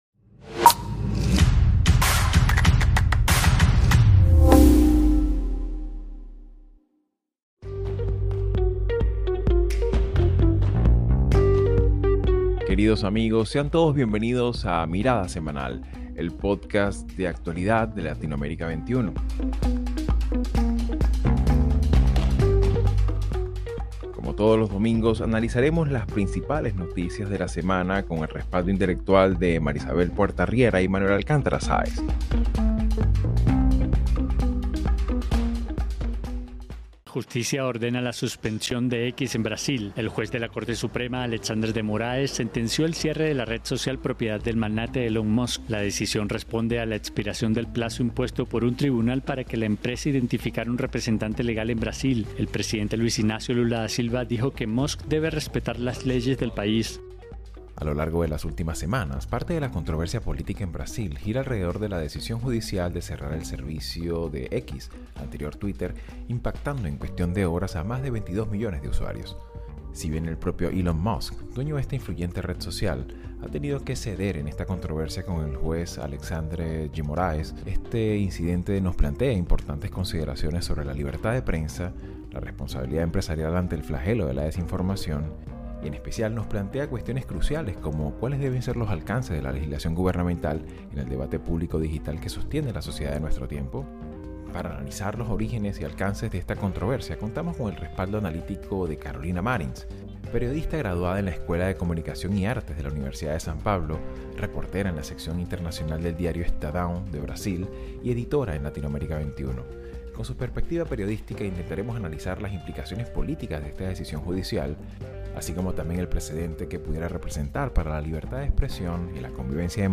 Mirada Semanal | La restricción de X en Brasil, su implicación para la libertad de expresión. Entrevista